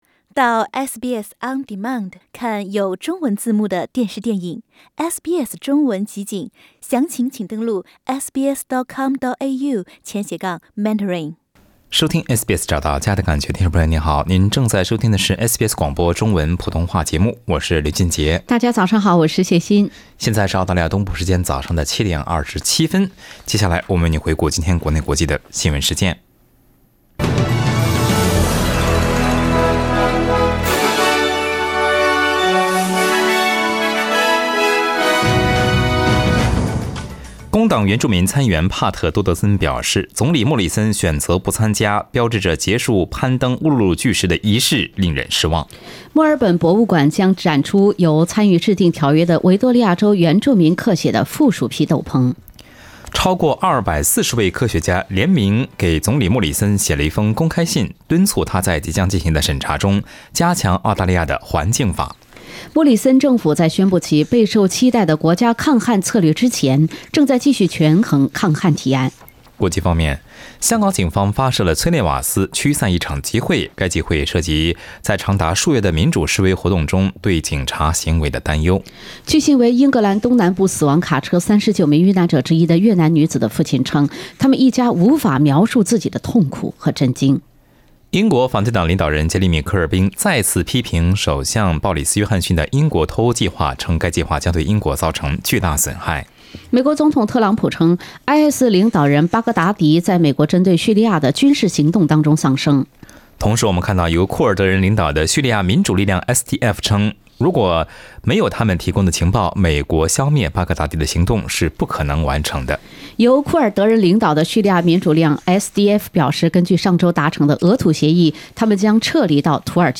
SBS早新闻（10月28日）